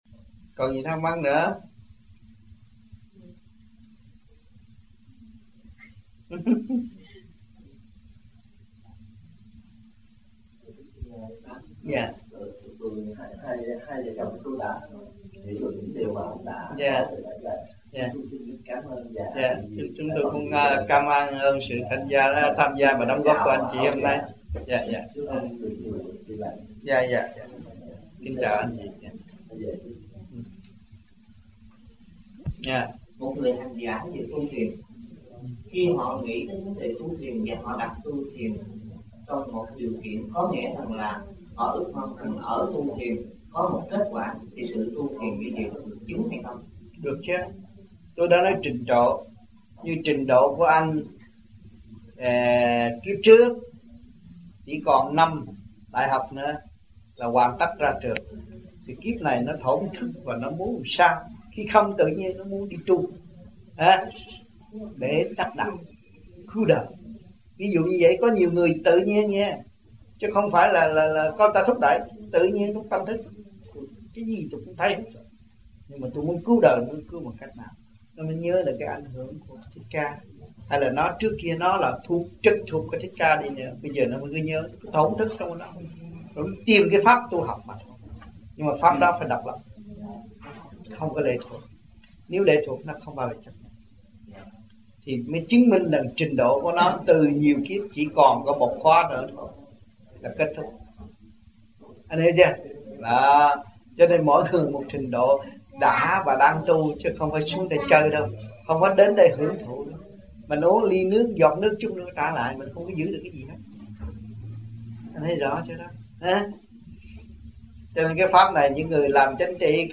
1982 Khóa Học Toulouse
1982-12-16 - Toulouse - Thuyết Pháp 2